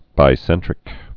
(bī-sĕntrĭk)